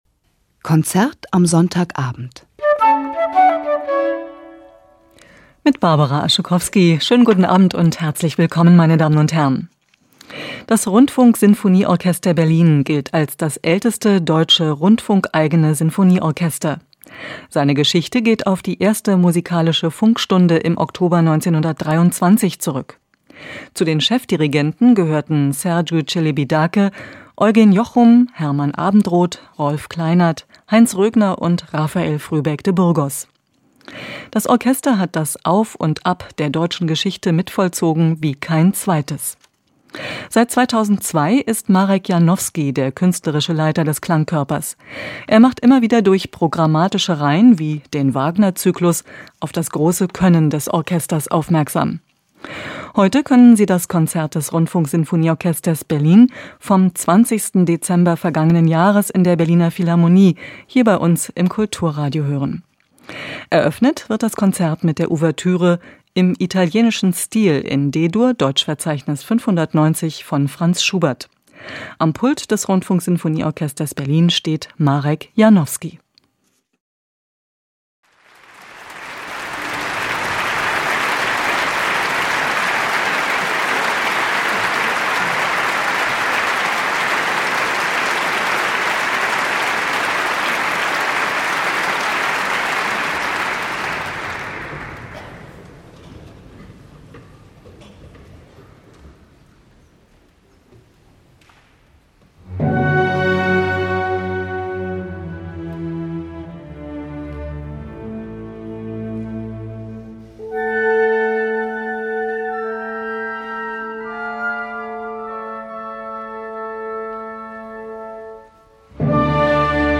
– Berlin Radio Symphony – Marek Janowski, Cond. – Arabella Steinbacher, violin – Dec. 20, 2011 – Berlin Radio – Part 1 –
Over to Berlin this week for a classic concert from 2011, featuring the Berlin Radio Symphony, conducted by Marek Janowski, and featuring the celebrated German/Japanese violinist Arabella Steinbacher in music of Schubert, Mozart and Brahms.
Calm, peaceful – fiery in all the right places and enjoyable everywhere else.